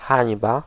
Ääntäminen
Ääntäminen Tuntematon aksentti: IPA: /ˈsxaːm.tə/ Haettu sana löytyi näillä lähdekielillä: hollanti Käännös Ääninäyte 1. wstyd {m} 2. hańba {f} Suku: f .